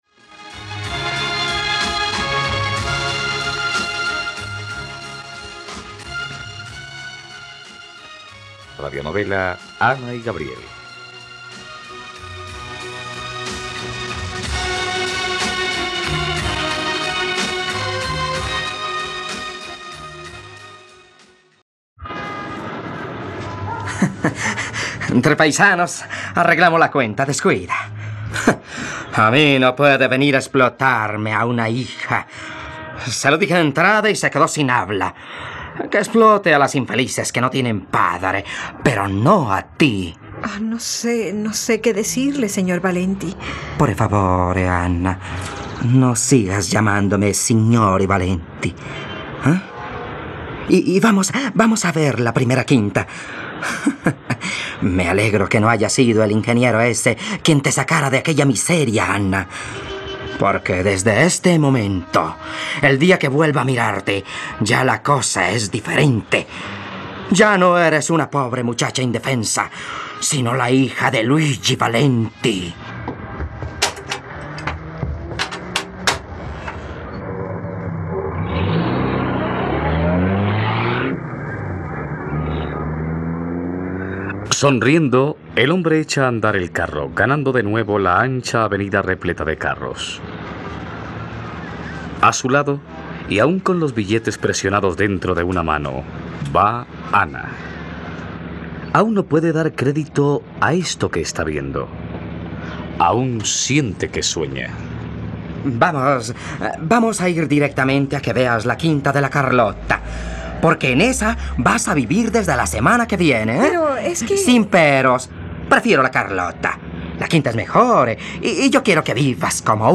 ..Radionovela. Escucha ahora el capítulo 72 de la historia de amor de Ana y Gabriel en la plataforma de streaming de los colombianos: RTVCPlay.